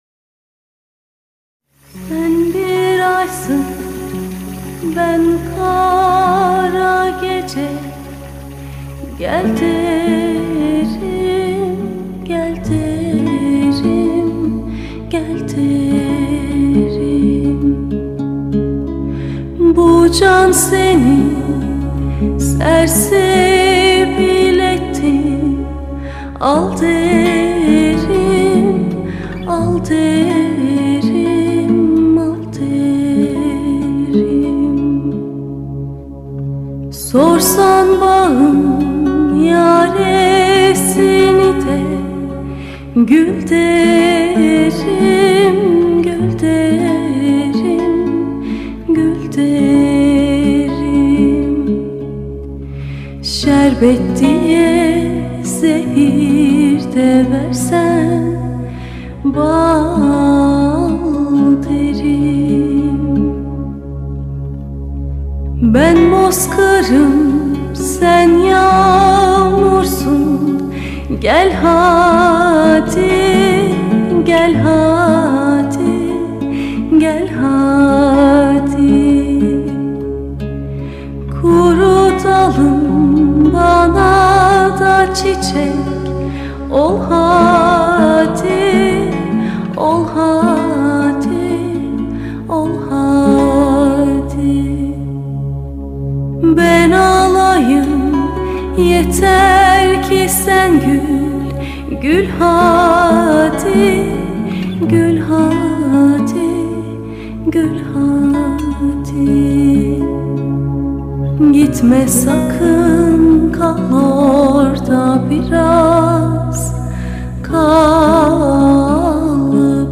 duygusal hüzünlü rahatlatıcı şarkı.